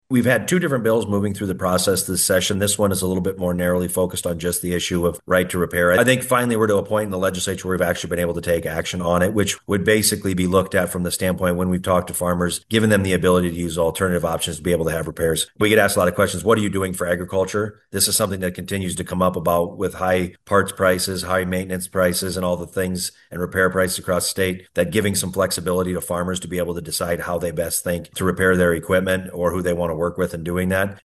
The proposal would require manufacturers to provide access to parts, tools, and diagnostic information—something Grassley says is long overdue for modern, high-tech farm machinery.